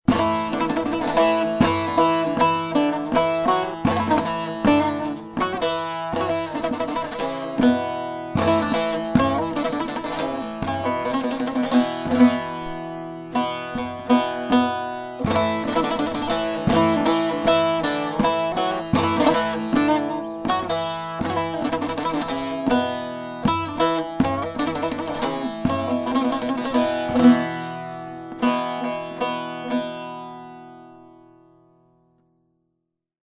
Sürmeli-stijl
Deze methode is geschreven voor langhals Saz met 23 fretten.